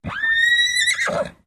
Whinnies
Horse Whinnies & Blows 1